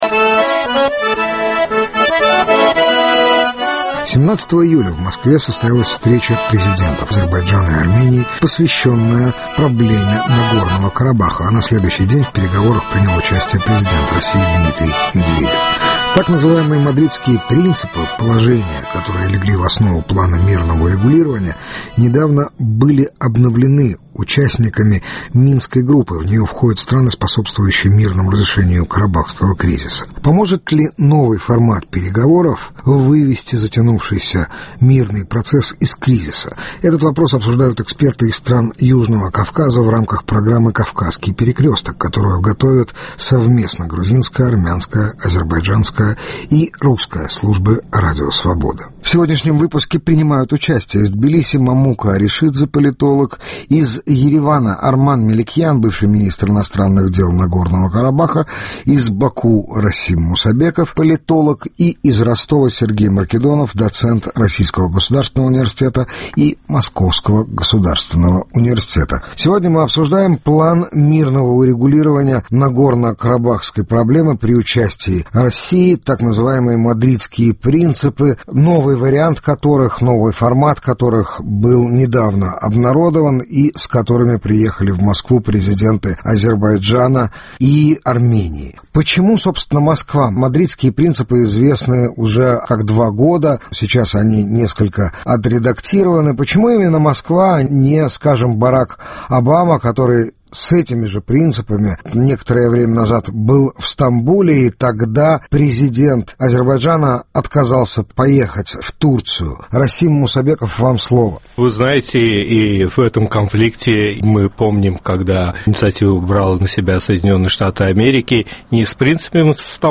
Мадридские приципы Карабахского урегулирования и участие России в процессе мирных переговоров - круглый стол